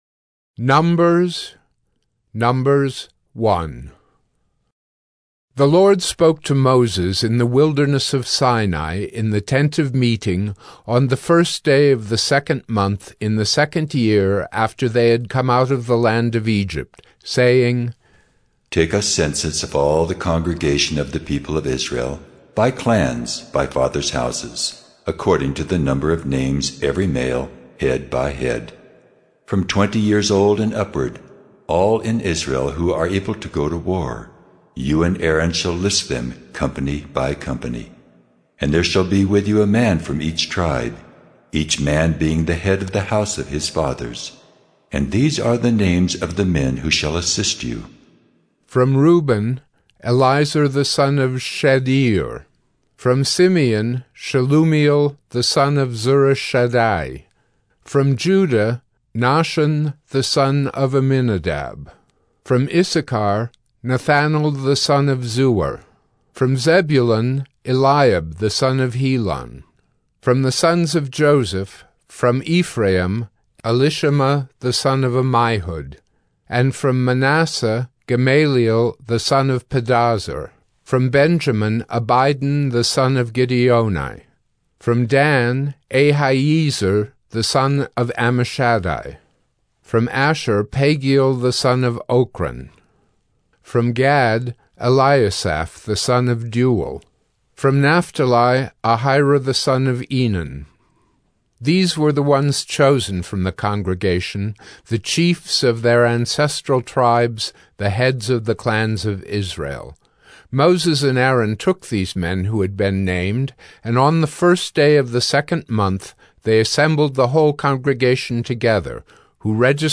Audio Bibles